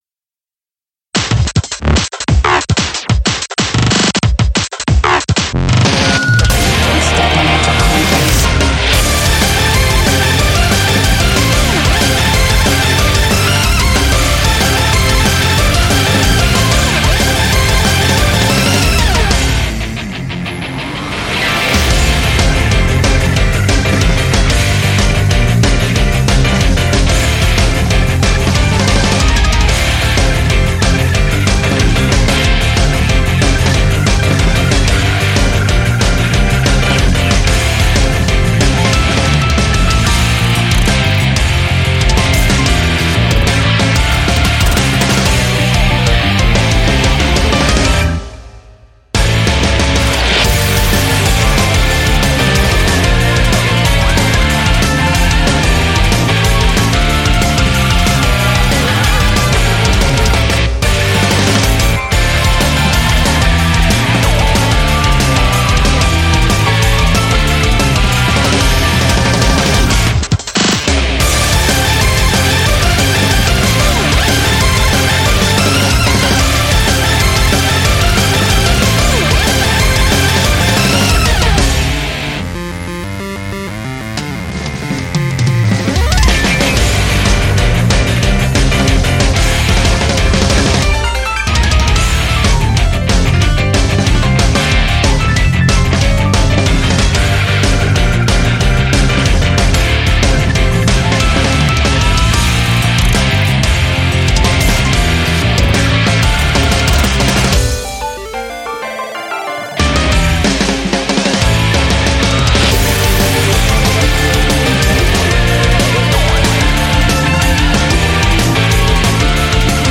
インスト　ダウンロードも可能。